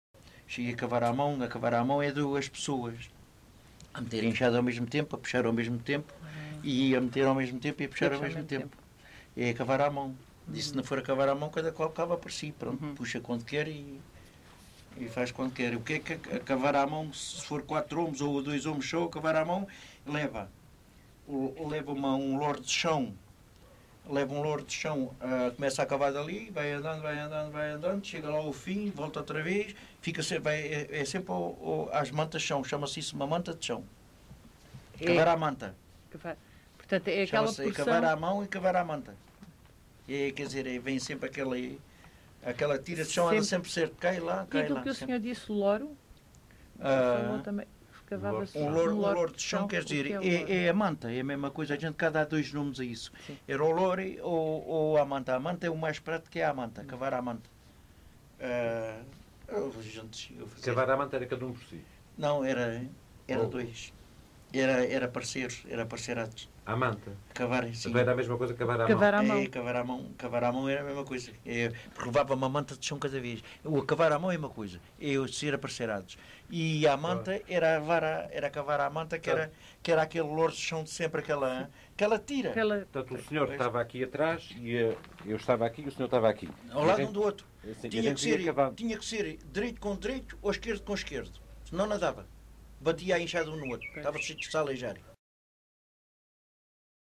LocalidadeEnxara do Bispo (Mafra, Lisboa)